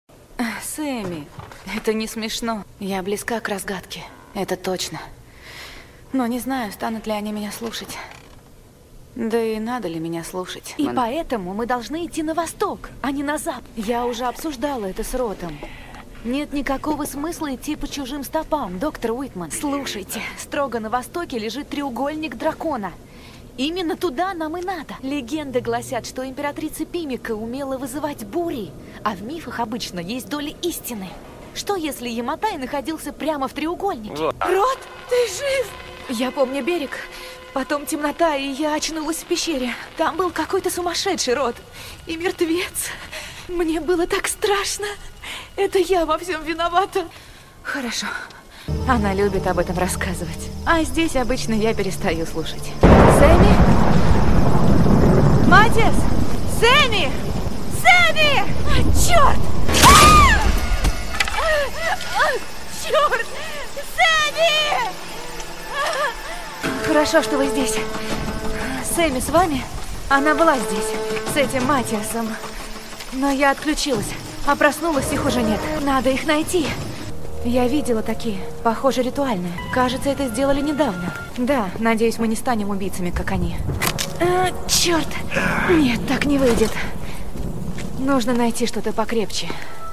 Образец голоса: